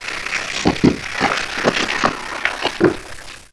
ALIEN_Communication_18_mono.wav